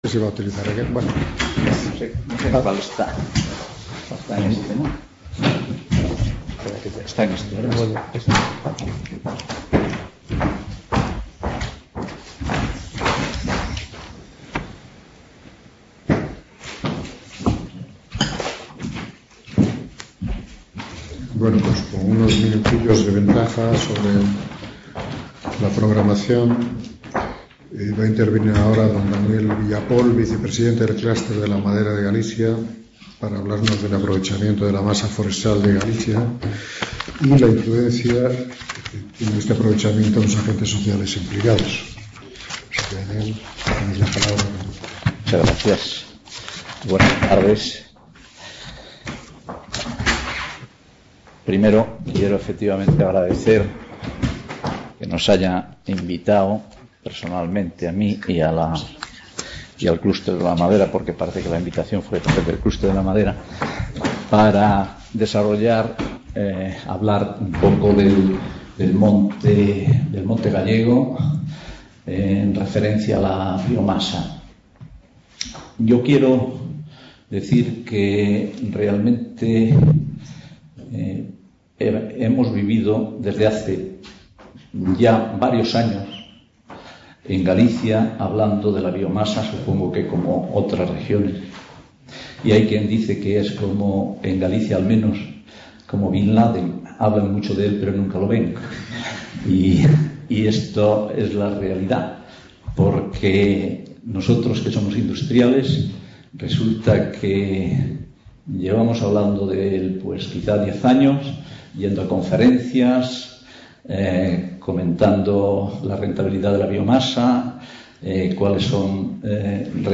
Simposio sobre Recursos energéticos de la biomasa y del viento